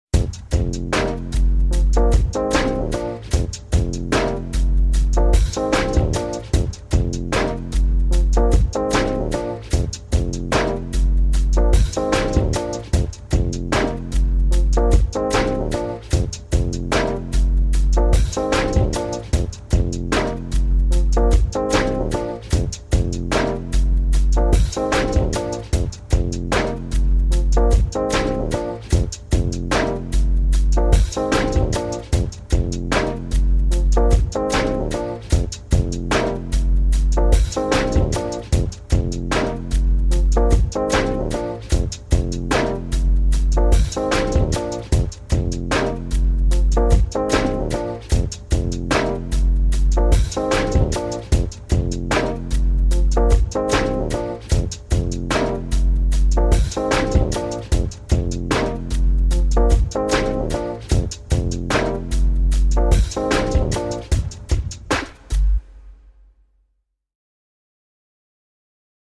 Music Bed; Laid Back Hip-hop Drum Loop Dance Groove.